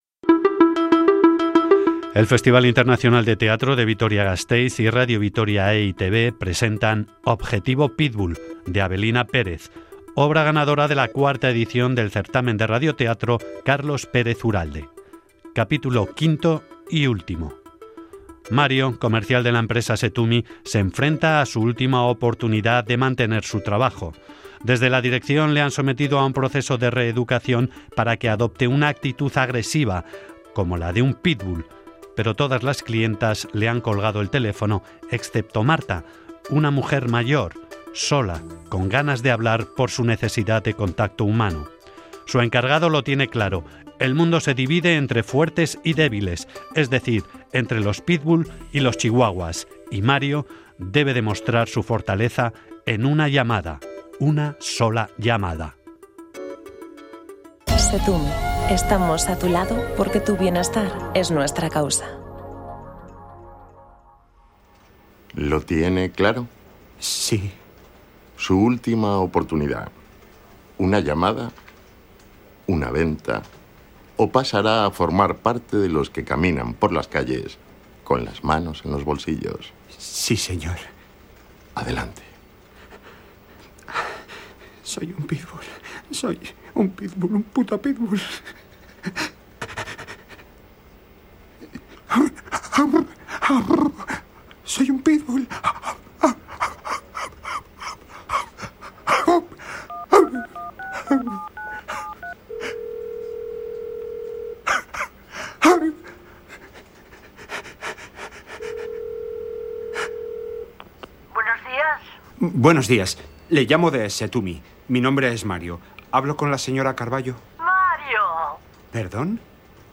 Radionovela
Grabado en Sonora Estudios.